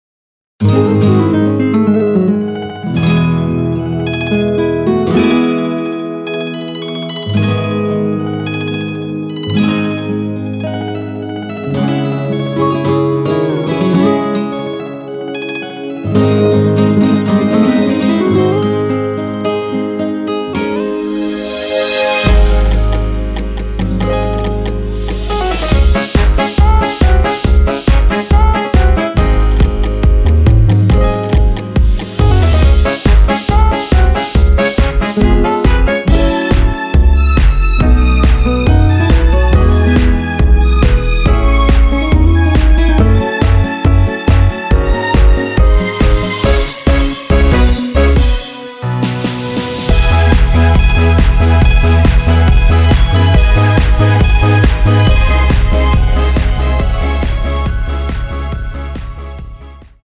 원키에서(-1)내린 MR입니다.
Dbm
앞부분30초, 뒷부분30초씩 편집해서 올려 드리고 있습니다.